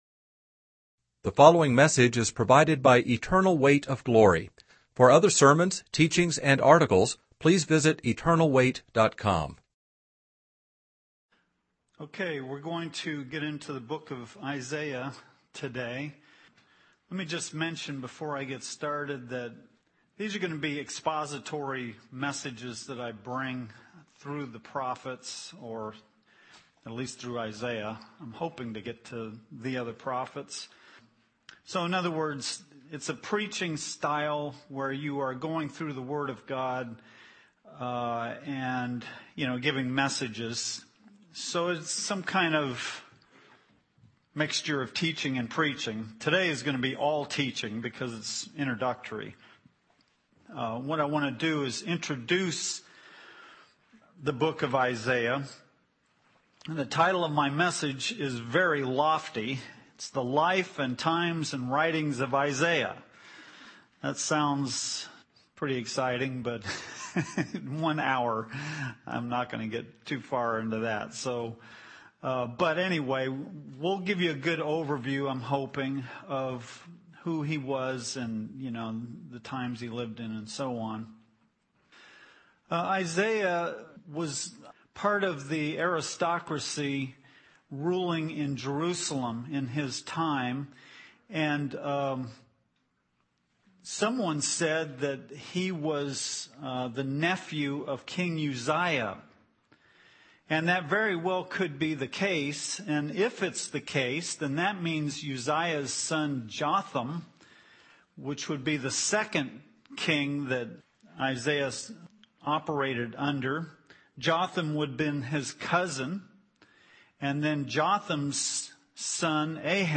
In this sermon, the speaker discusses the book of Isaiah and its structure. The book is divided into several sections, each with a different focus. The first section denounces Judah, while the second section denounces other nations.